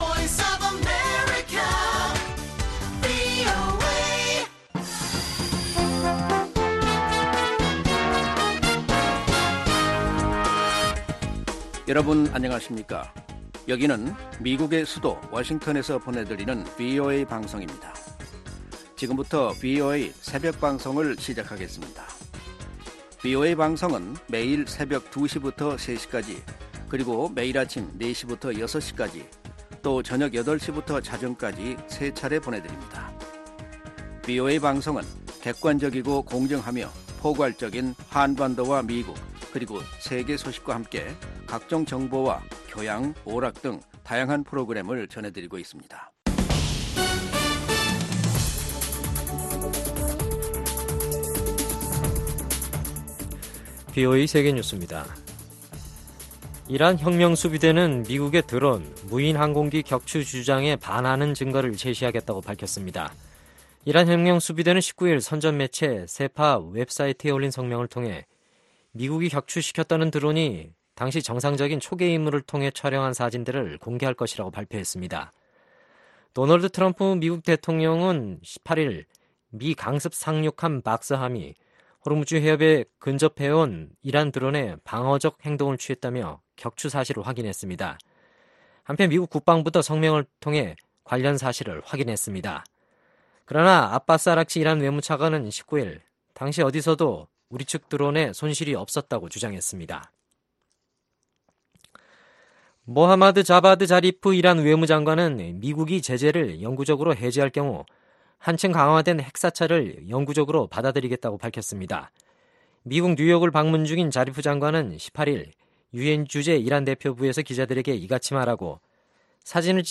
VOA 한국어 '출발 뉴스 쇼', 2019년 7월 20일 방송입니다. 미국은 북한과의 비핵화 협상 중에도 지속적으로 북한 주민들의 종교자유를 지지할 것이라고 마이크 펜스 부통령이 밝혔습니다. 미-북 실무 협상 재개가 늦춰지고 있는 가운데 미국의 상원의원들은 약속을 어기고 시간을 끄는 북한의 행태는 변하지 않았다고 비판했습니다.